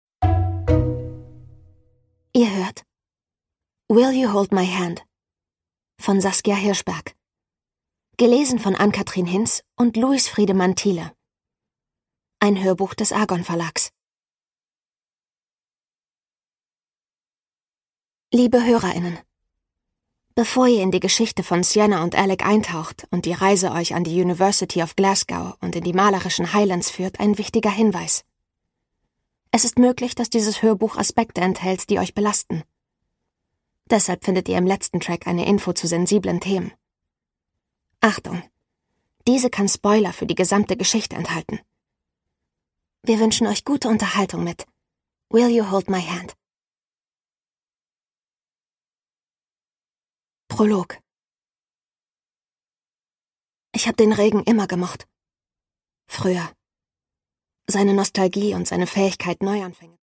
Saskia Hirschberg: Will You Hold My Hand? (Ungekürzte Lesung)
Produkttyp: Hörbuch-Download